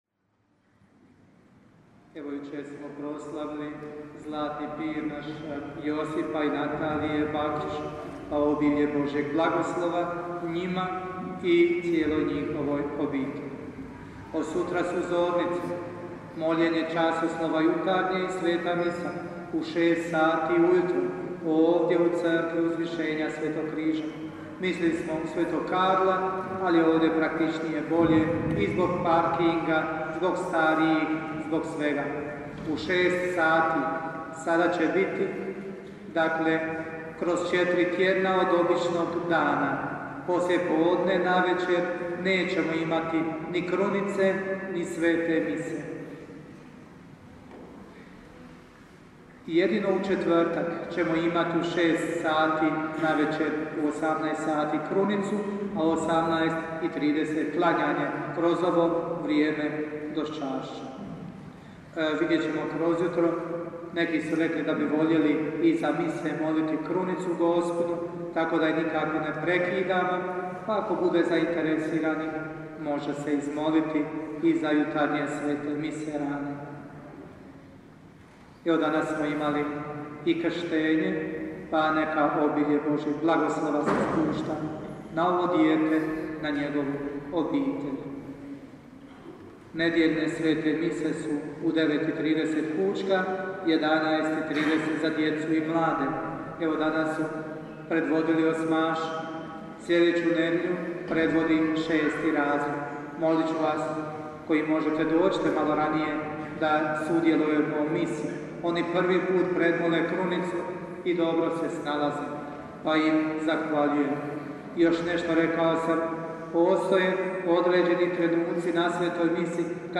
OBAVIJESTI: